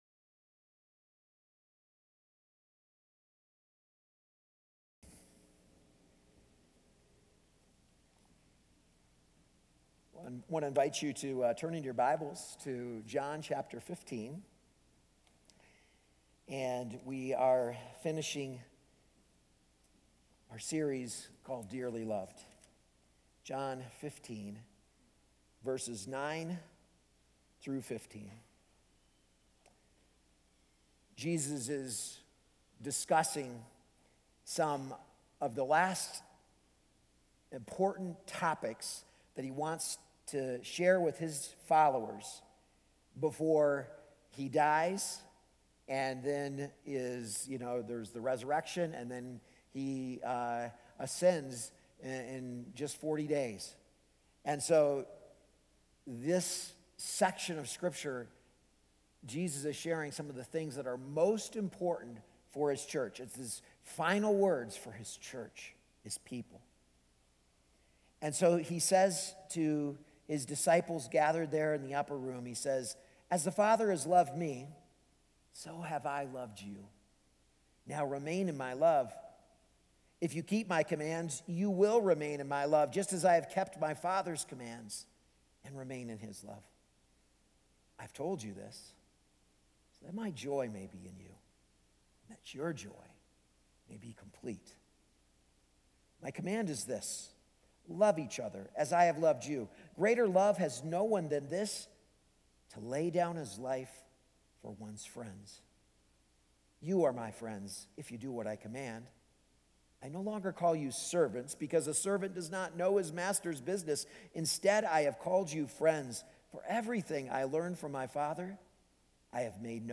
A message from the series "Dearly Loved."